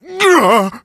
ruff_hurt_vo_09.ogg